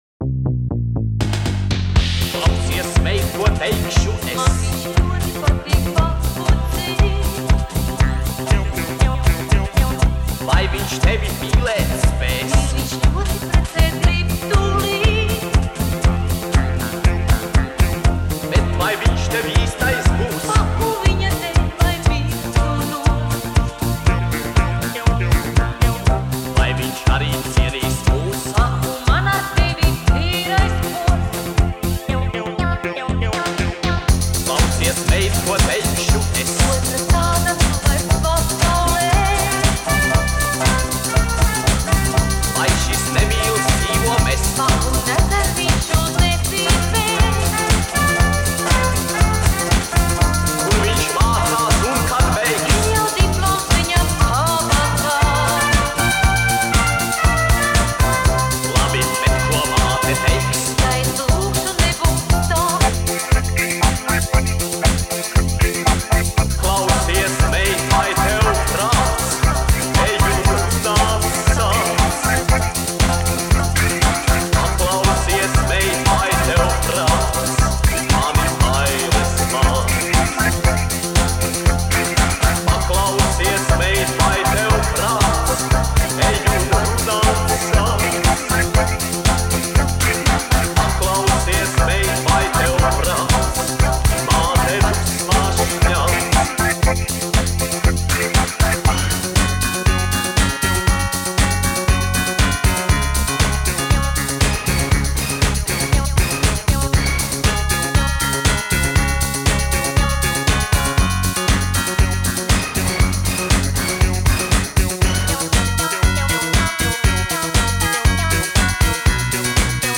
Populārā mūzika
Dziesmas